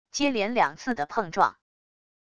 接连两次的碰撞wav音频